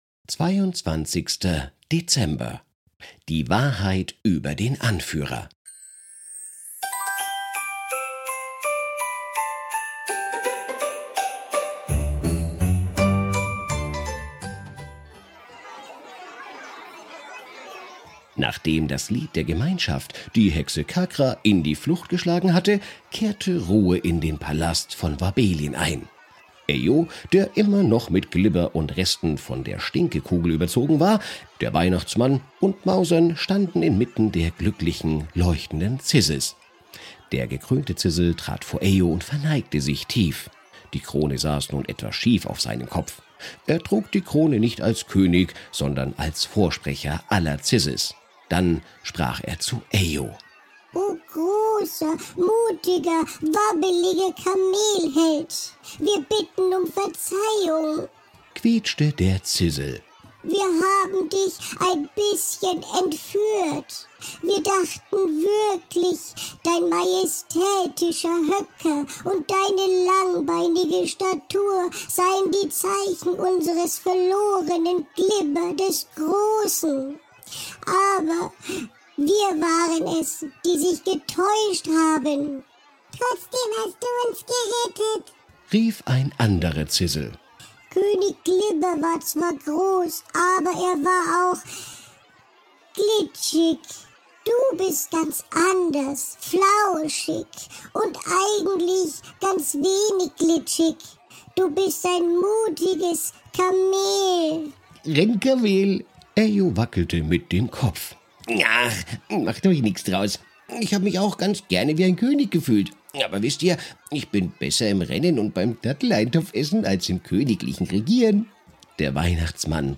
Ein Kinder Hörspiel Adventskalender
VoiceOver: